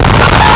Player_Glitch [21].wav